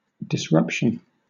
Ääntäminen
IPA : /dɪsˈɹʌpʃən/